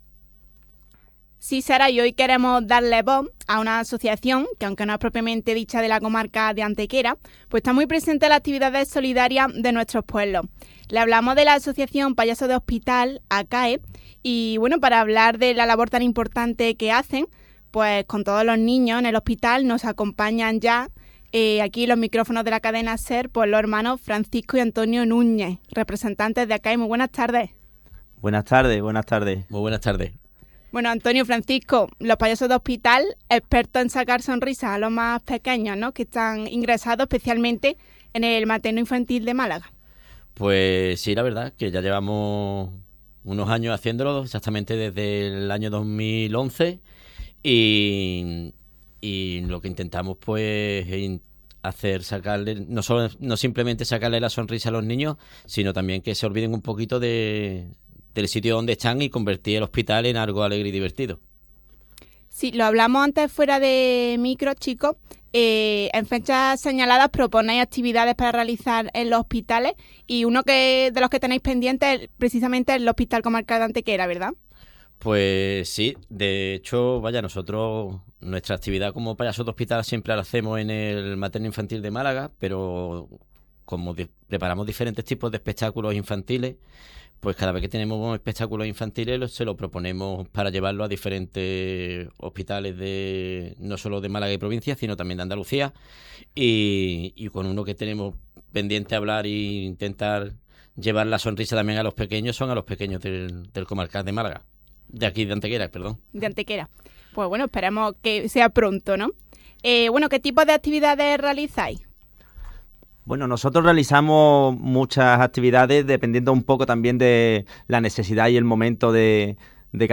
Entrevista ACAE Payasos de Hospital